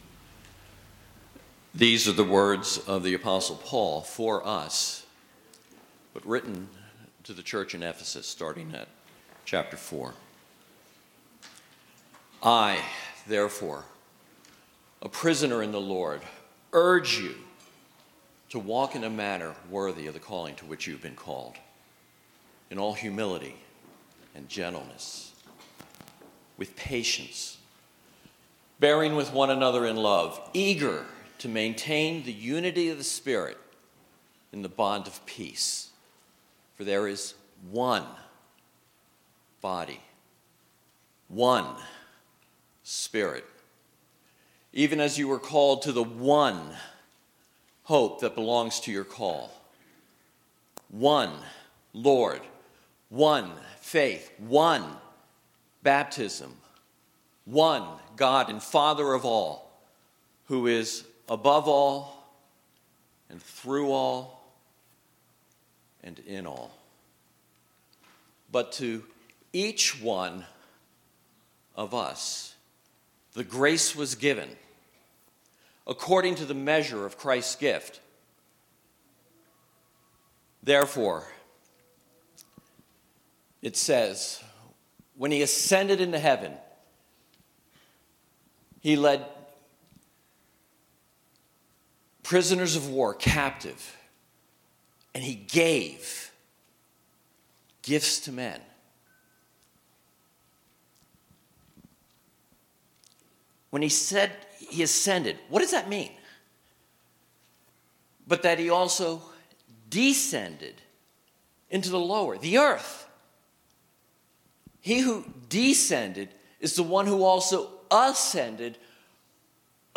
Sermons | Staunton Alliance Church